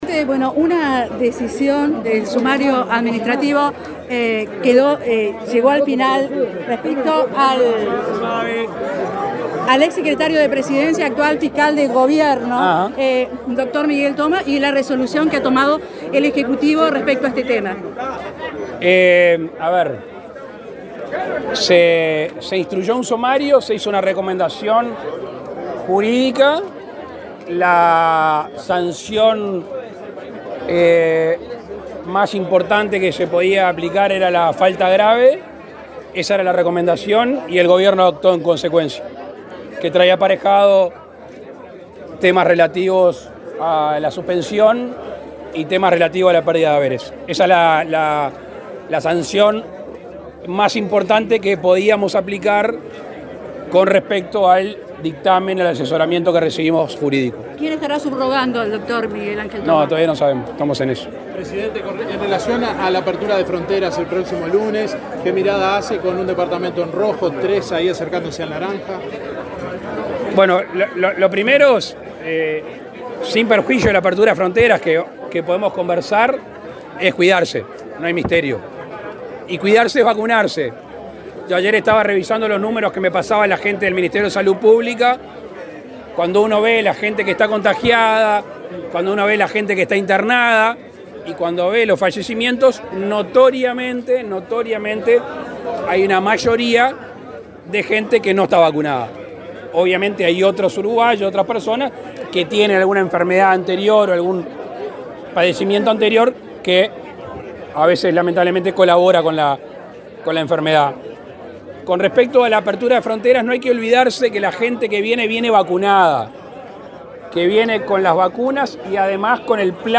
Declaraciones a la prensa del presidente Lacalle Pou
El presidente Luis Lacalle Pou encabezó el acto de inauguración de obras en el puerto de Nueva Palmira, Colonia, y, luego, dialogó con la prensa.